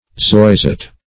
Search Result for " zoisite" : The Collaborative International Dictionary of English v.0.48: Zoisite \Zois"ite\, n. [After its discoverer, Von Zois, an Austrian mineralogist.]